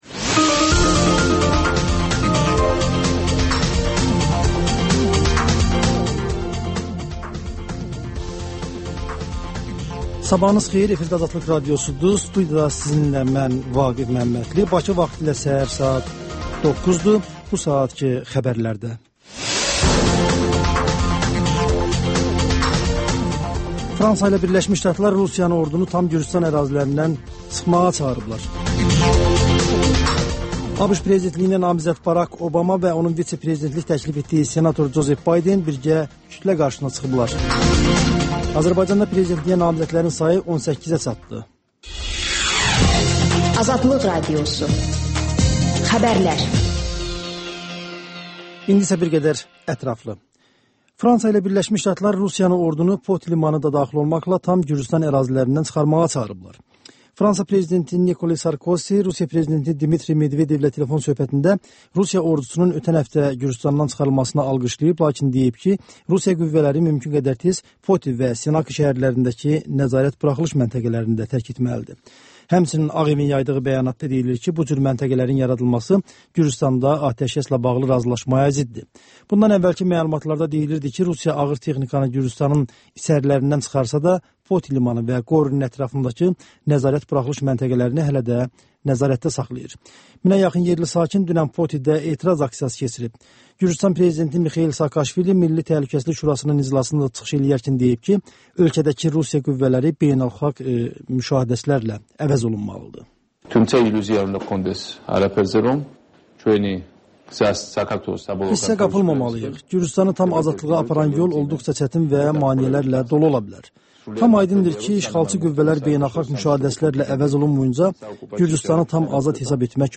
Xəbərlər, HƏMYERLİ: Xaricdə yaşayan azərbaycanlılar haqda veriliş, sonda MÜXBİR SAATI